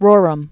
(rôrəm), Ned Born 1923.